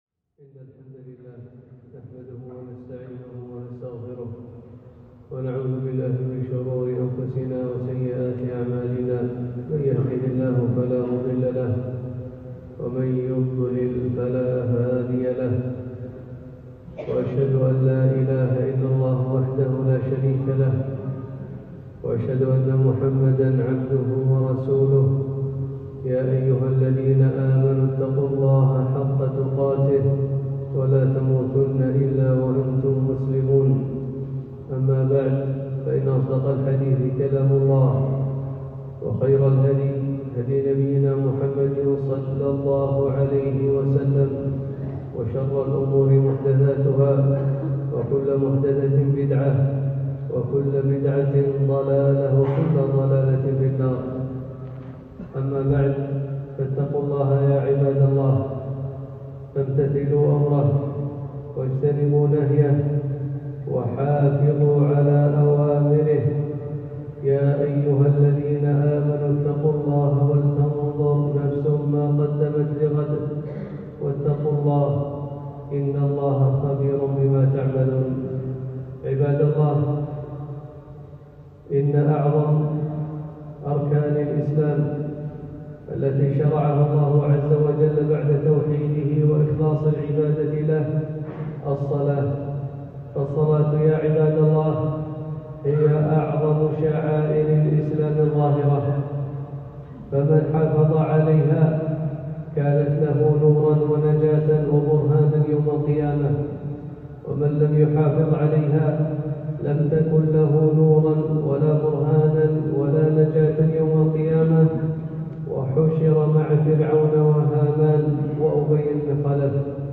خطبة - الصلاة فضلها وبعض أحكامها